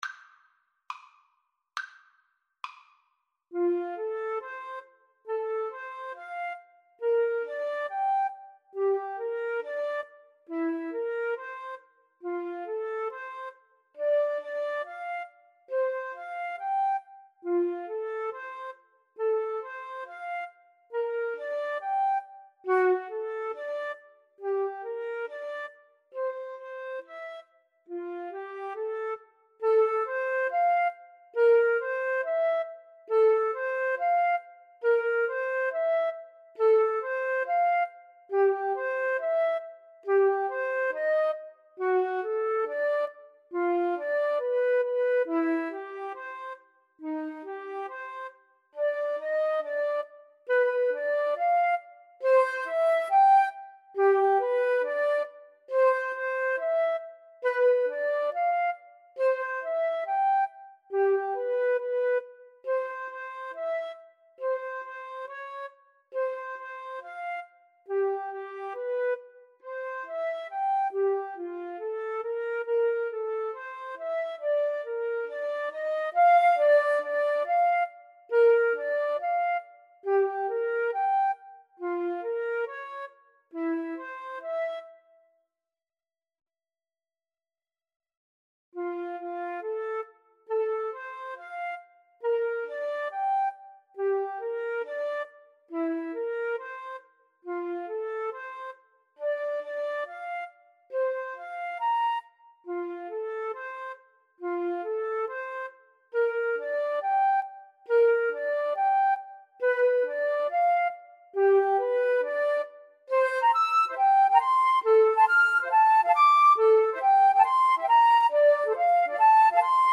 Free Sheet music for Flute Duet
F major (Sounding Pitch) (View more F major Music for Flute Duet )
~ = 69 Allegro grazioso (View more music marked Allegro)
2/4 (View more 2/4 Music)
Classical (View more Classical Flute Duet Music)